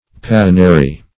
Search Result for " passionary" : The Collaborative International Dictionary of English v.0.48: Passionary \Pas"sion*a*ry\, n. [L. passionarius: cf. F. passionaire.] A book in which are described the sufferings of saints and martyrs.
passionary.mp3